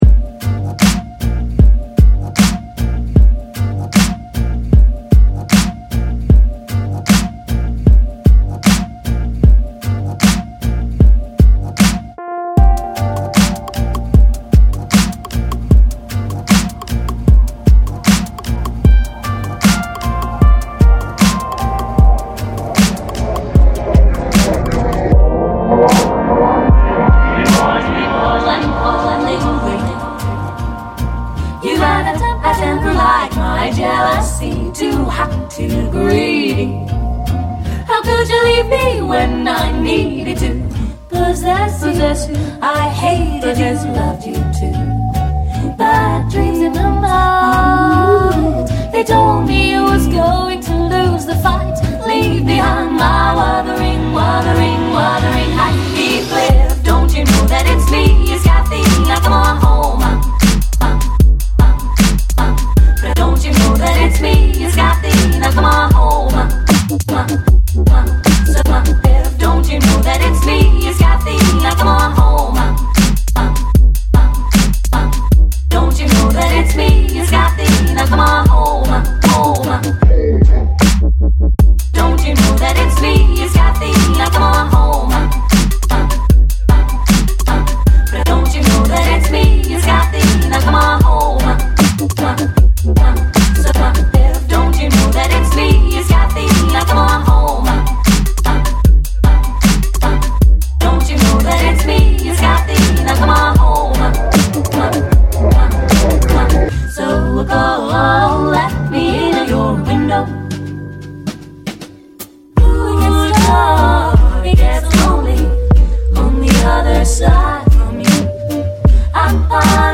shuffling modern beats and vintage swing sounds
heavier wobble bass pushes things up a gear or two